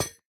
Minecraft Version Minecraft Version 1.21.5 Latest Release | Latest Snapshot 1.21.5 / assets / minecraft / sounds / block / copper_grate / step6.ogg Compare With Compare With Latest Release | Latest Snapshot